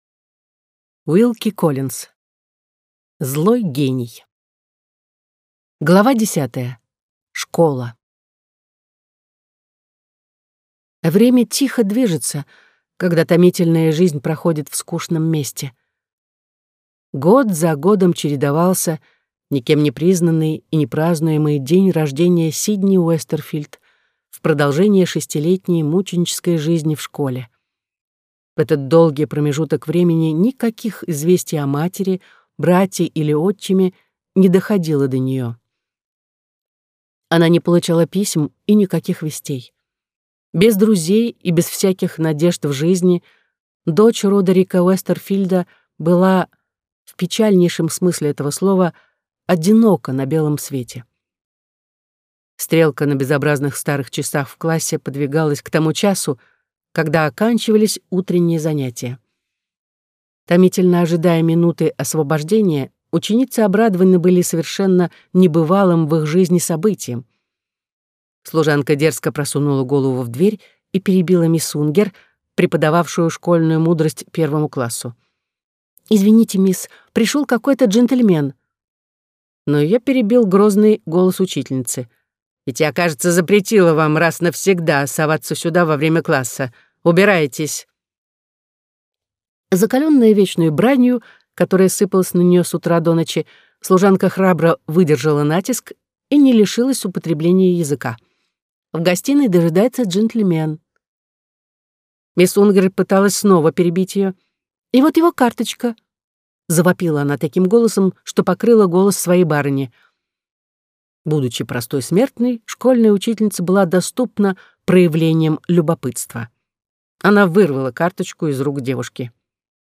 Аудиокнига Злой гений | Библиотека аудиокниг